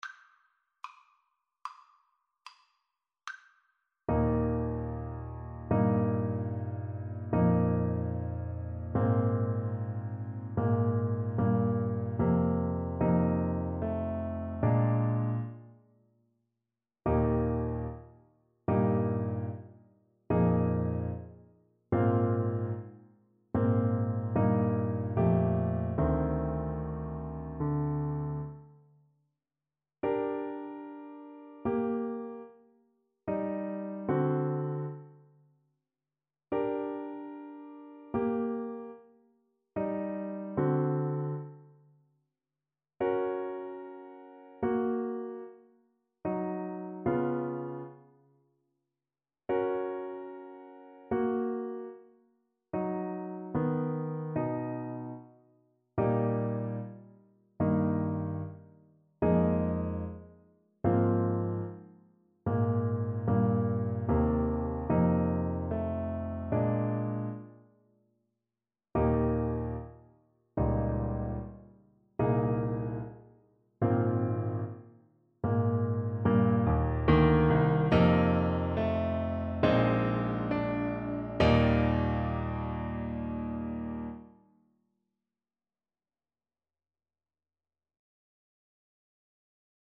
Eb major (Sounding Pitch) C major (Alto Saxophone in Eb) (View more Eb major Music for Saxophone )
Andante con moto =74 (View more music marked Andante con moto)
4/4 (View more 4/4 Music)
Classical (View more Classical Saxophone Music)